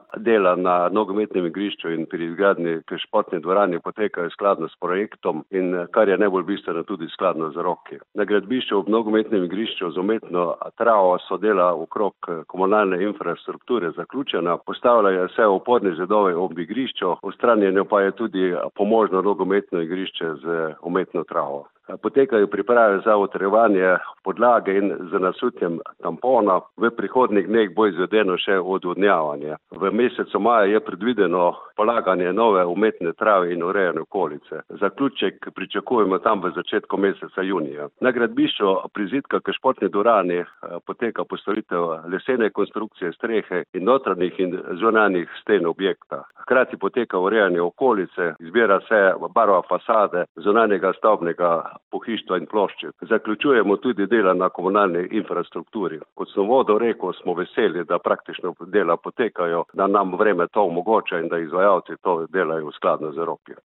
Kako potega gradnja in kaj se trenutno dogaja na obeh gradbiščih, je pojasnil podžupan Mestne občine Slovenj Gradec Peter Pungartnik.
izjava Peter Pungartnik - sportna dvorana za splet.mp3